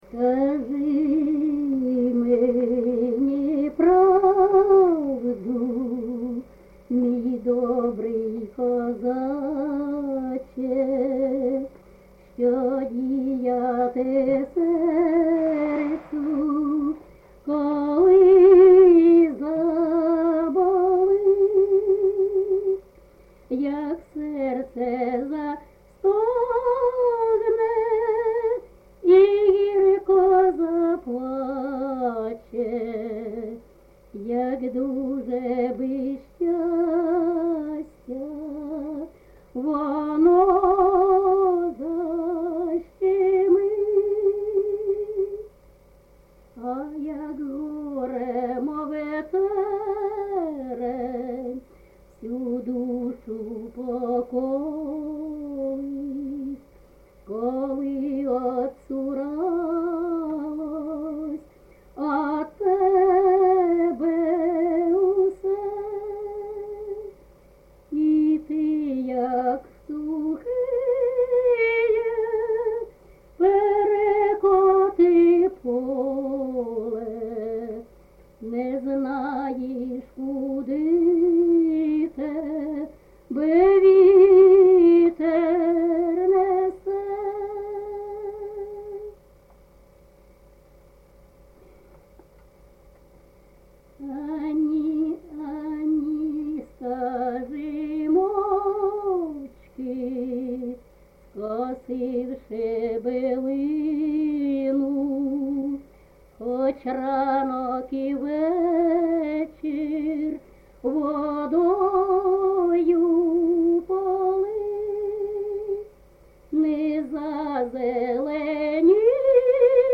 ЖанрПісні літературного походження, Сучасні пісні та новотвори
Місце записус-ще Михайлівське, Сумський район, Сумська обл., Україна, Слобожанщина